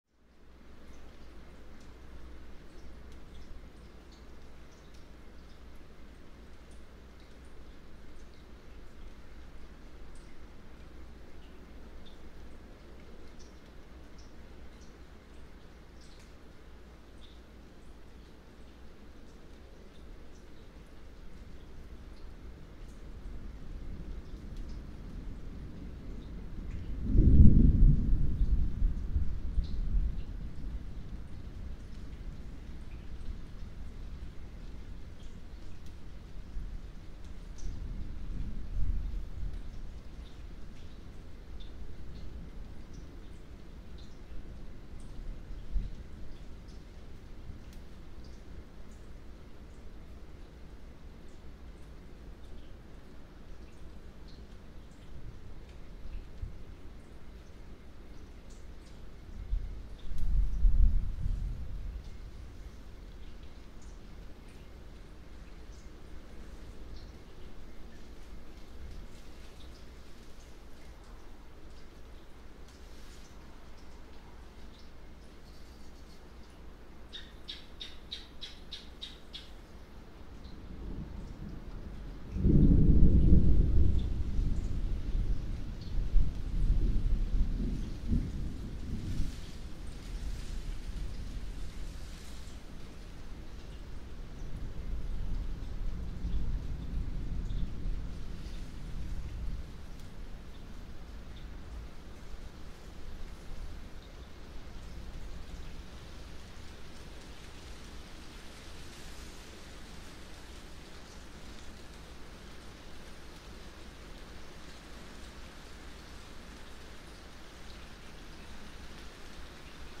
rain-sounds-for-sleeping-studying-relaxing-and-meditating.mp3